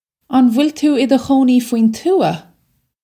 Pronunciation for how to say
On vwill too ih duh khoh-nee fween too-ah?
This is an approximate phonetic pronunciation of the phrase.